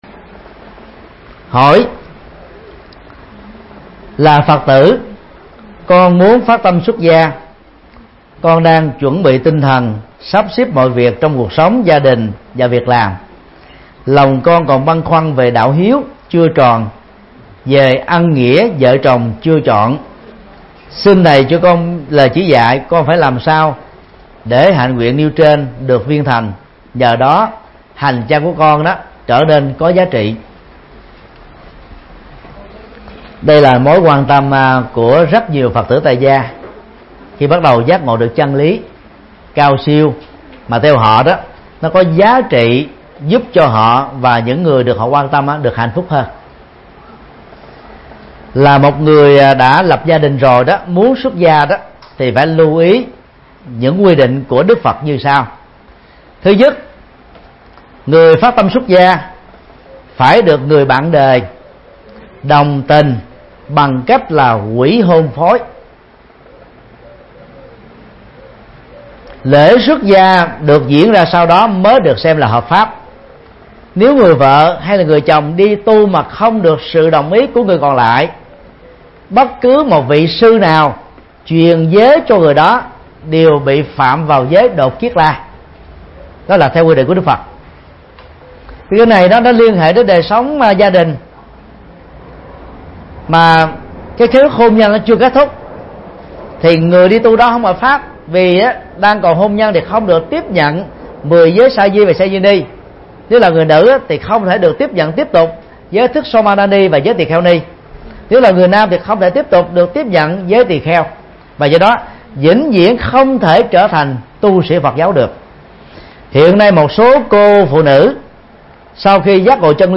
Thuyết Giảng
Vấn đáp: Phát tâm xuất gia